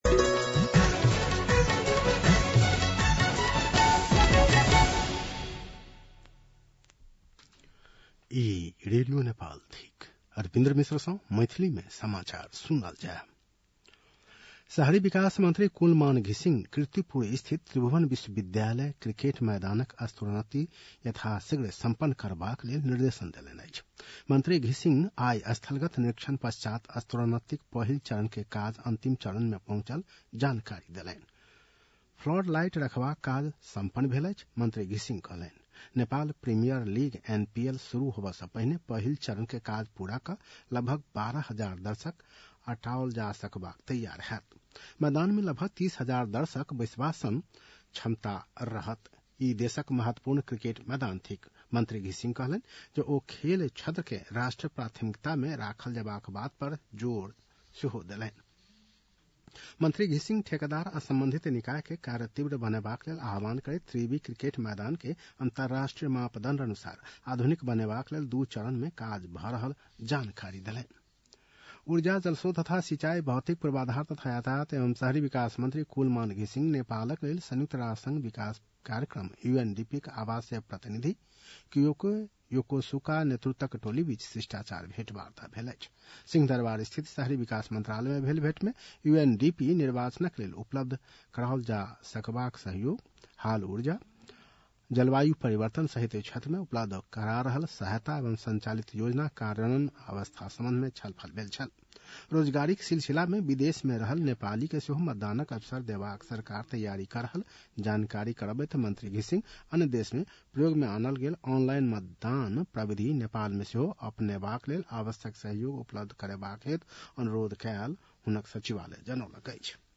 मैथिली भाषामा समाचार : २३ असोज , २०८२
6.-pm-maithali-news-1-2.mp3